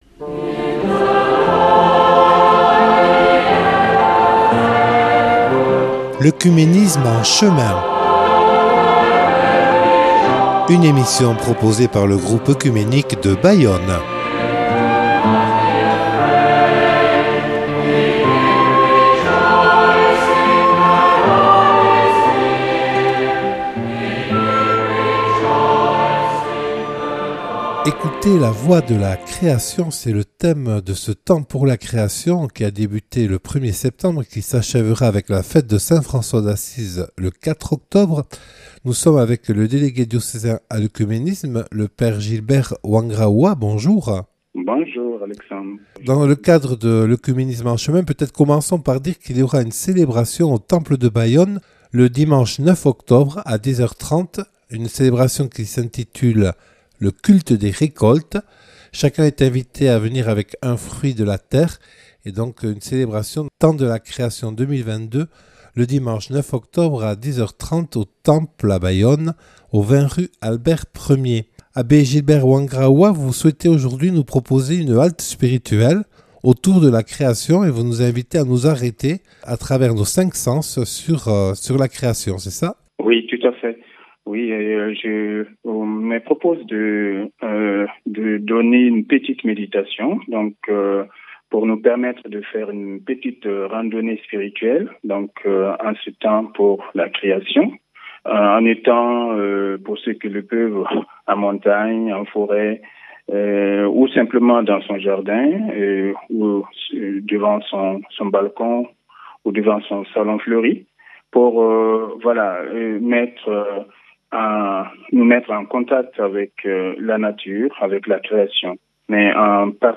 Méditation